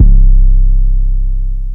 Mutumbo 808.wav